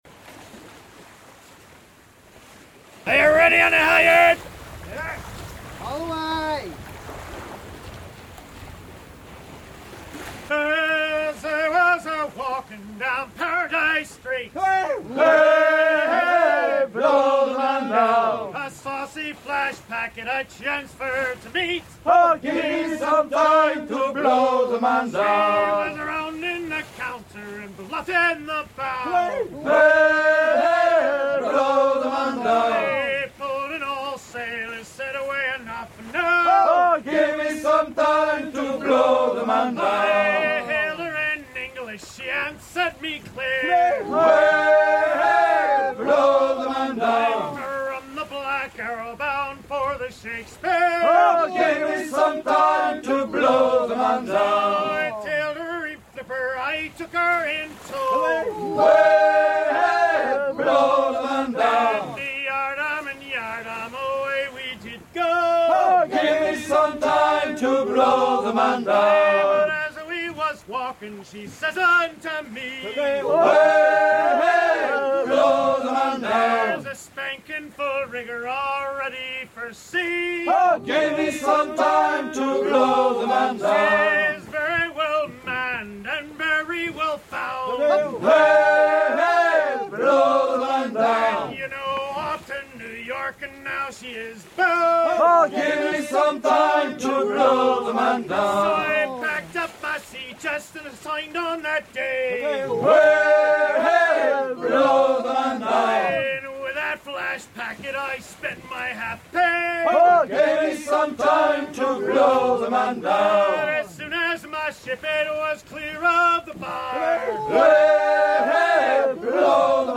chanté en hissant le hunier volant de la goélette
Pièce musicale éditée